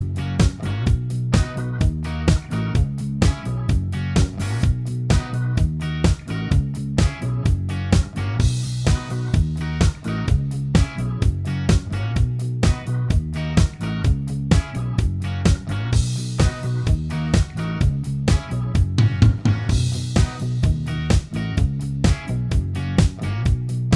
Minus All Guitars Pop (1970s) 3:35 Buy £1.50